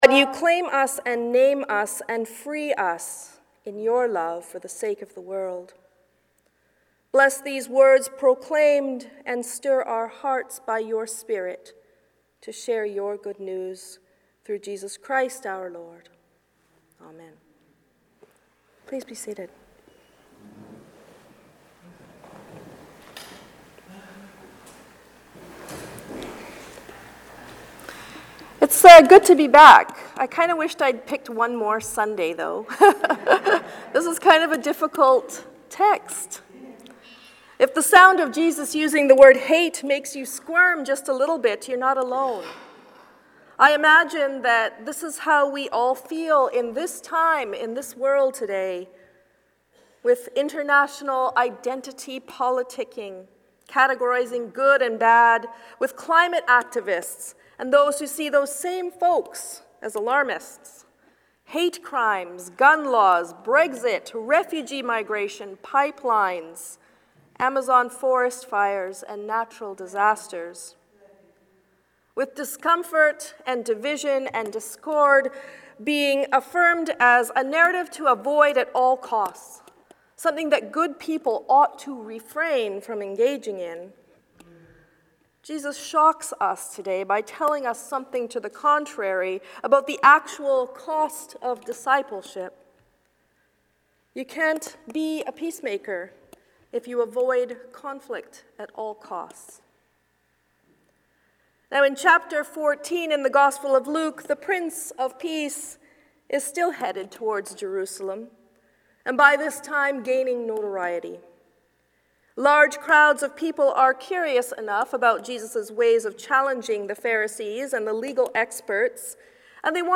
Sermons | St. Marys' Metchosin